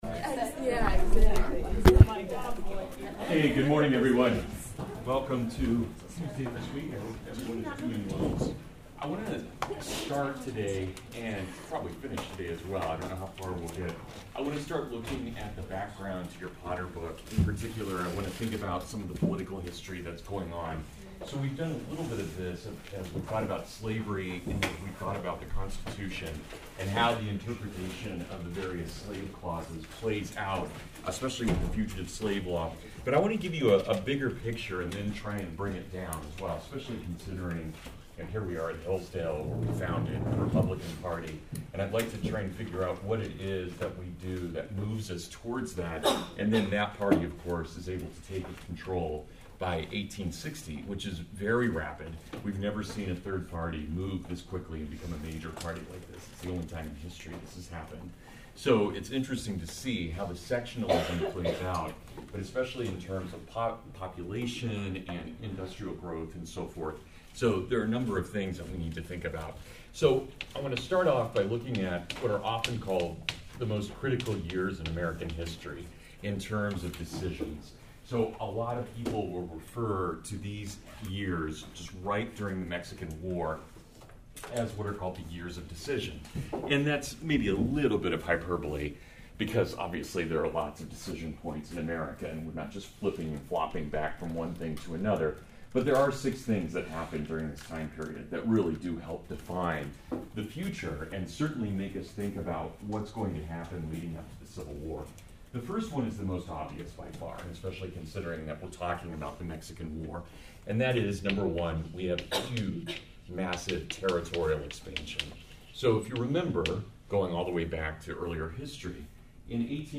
A lecture from my Civil War course--how the republic fell apart, 1846-1856. From invading Mexico to the sack of Lawrence.